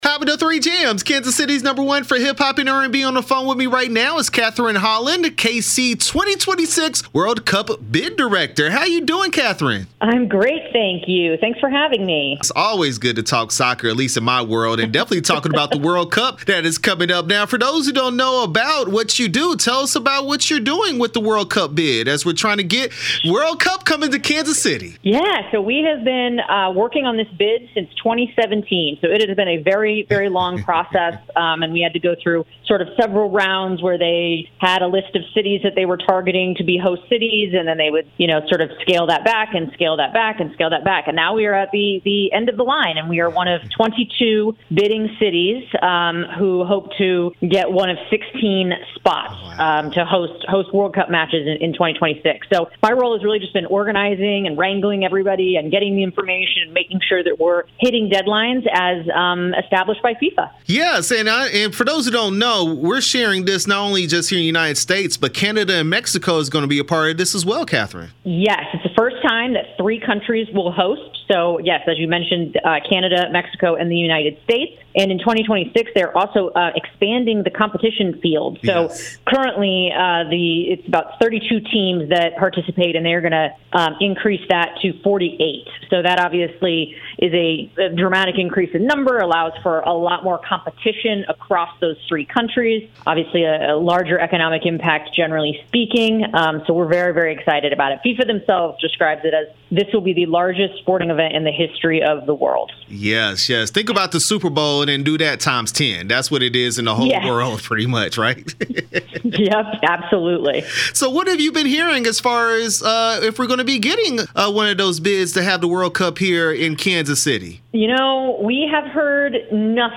KC2026 World Cup Bid Announcement interview 6/10/22